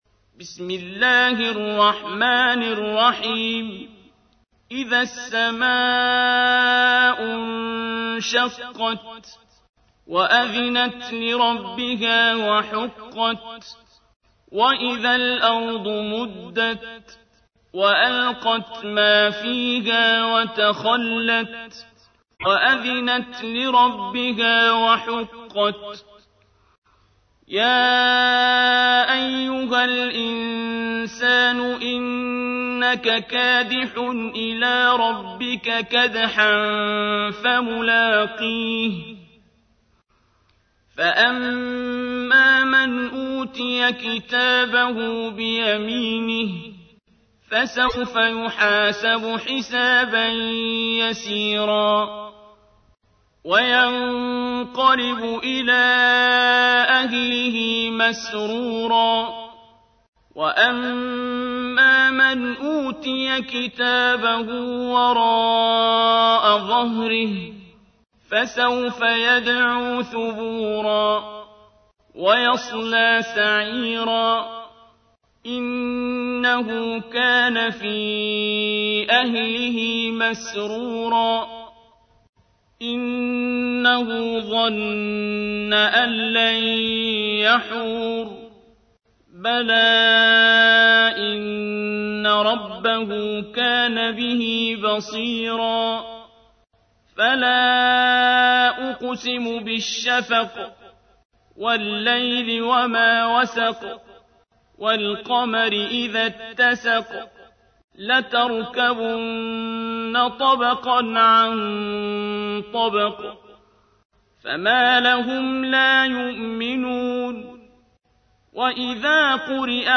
تحميل : 84. سورة الانشقاق / القارئ عبد الباسط عبد الصمد / القرآن الكريم / موقع يا حسين